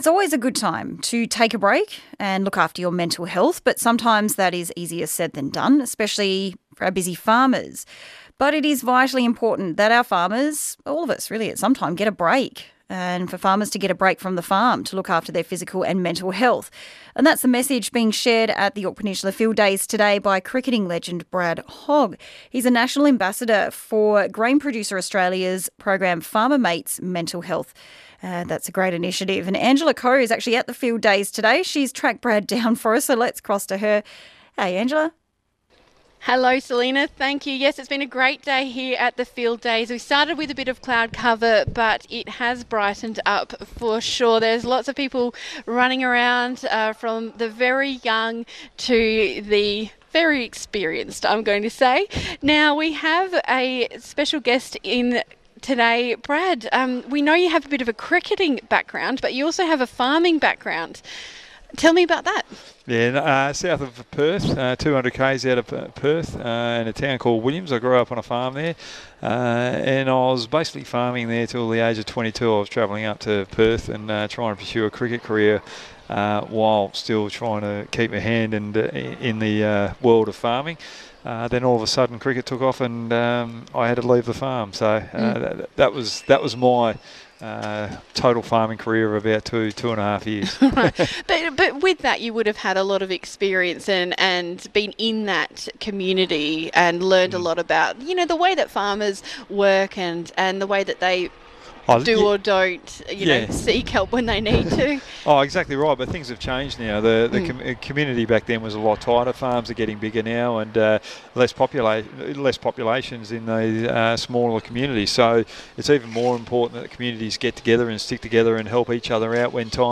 Cricketing legend Brad Hogg spoke to ABC SA Country Hour at the Yorke Peninsula Field Days at Paskeville, in South Australia, highlighting the vital importance of farmers taking a break from the farm, to stay mentally and physically fit, fresh and healthy.